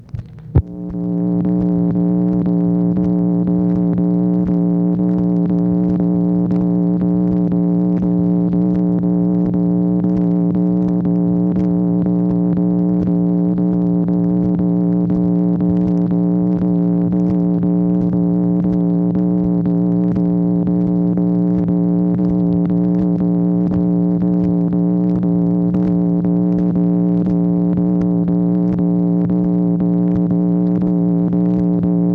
MACHINE NOISE, July 17, 1964
Secret White House Tapes | Lyndon B. Johnson Presidency